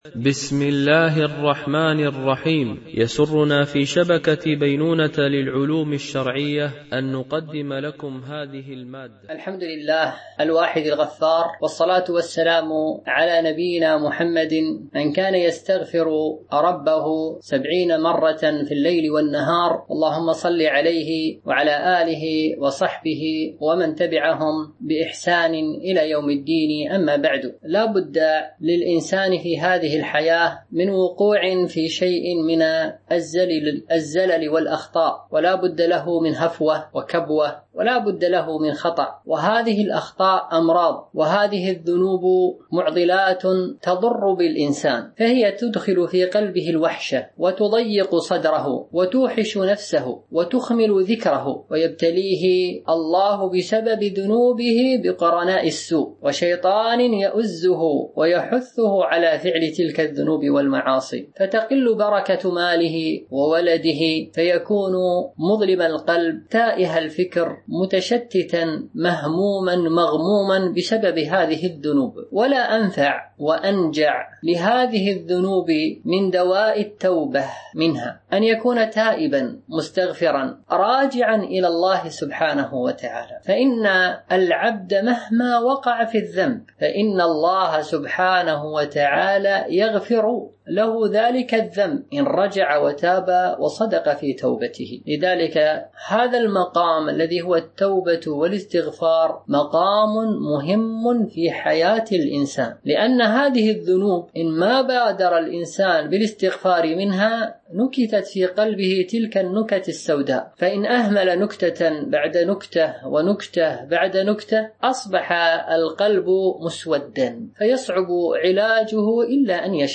سلسلة محاضرات نسائم إيمانية وقيم أخلاقية